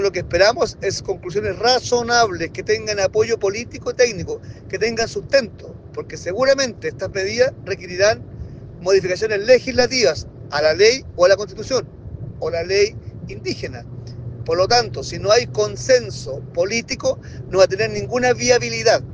Por su parte, su par de la UDI, Henry Leal, señaló que la Comisión por La Paz y el Entendimiento debe entregar conclusiones razonables.